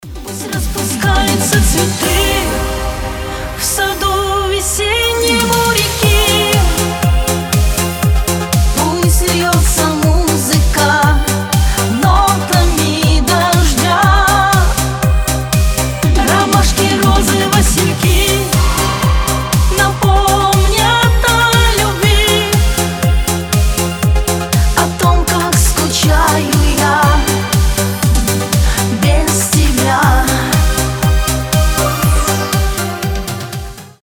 • Качество: 320, Stereo
душевные